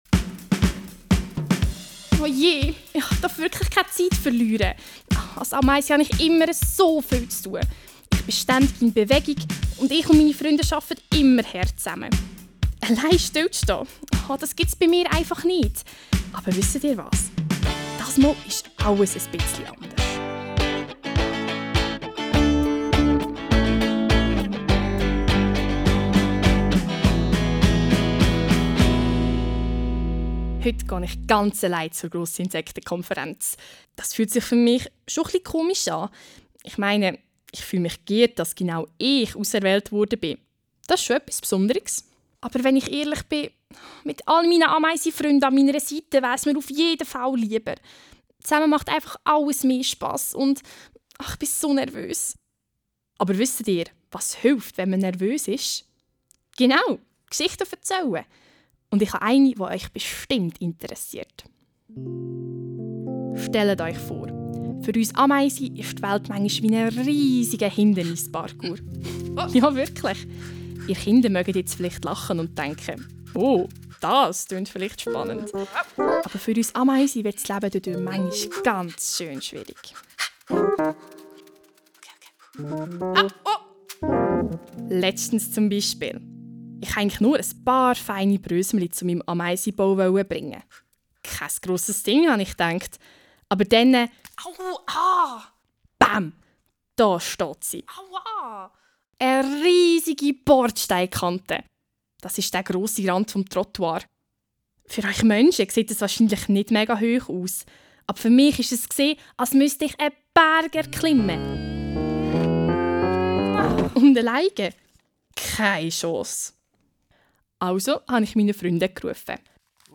LA Challenge Erde Die zweite Challenge startet mit einer Geschichte der Ameise Hektora. Ihr erfahrt mehr über die Herausforderungen einer kleinen Ameise in unserer heutigen Umwelt. Startet mit dem Hörspiel: Hört das Hörspiel über eure Lautsprecher.